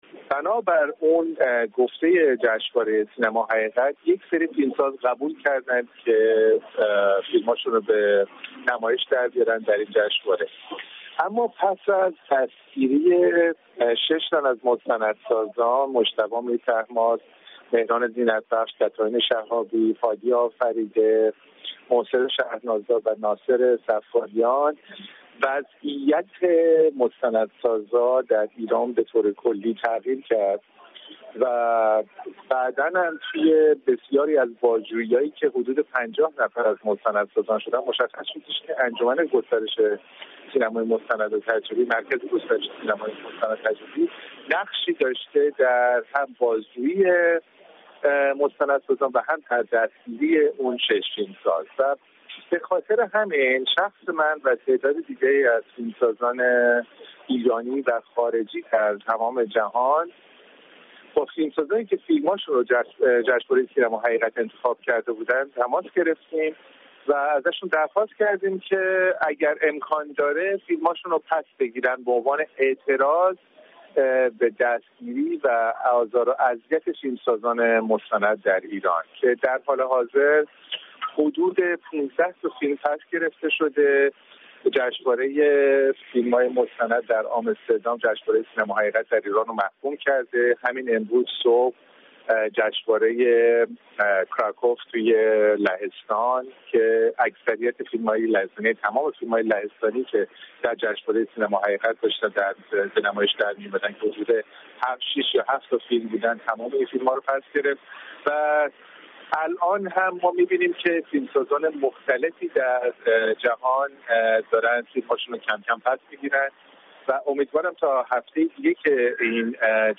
گفت‌وگو با مازیار بهاری، فیلمساز ایرانی مقیم لندن